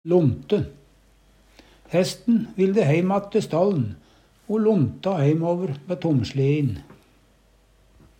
lonte - Numedalsmål (en-US)